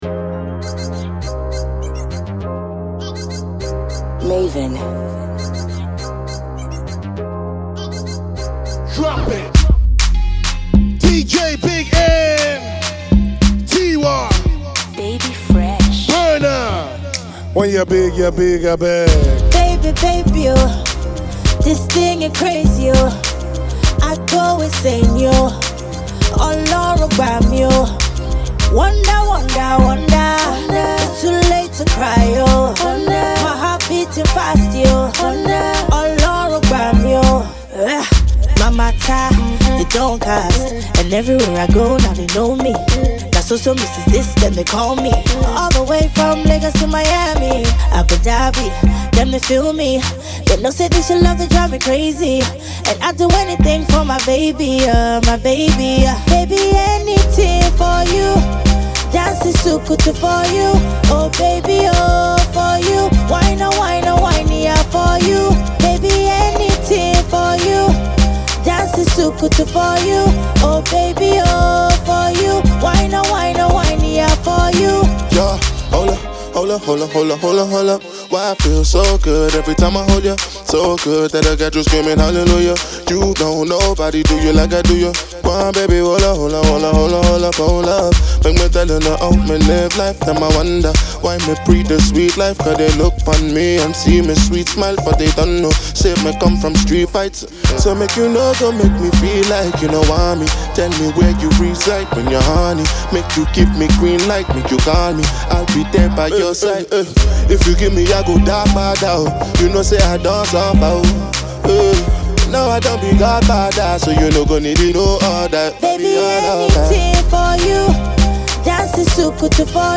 feel good sound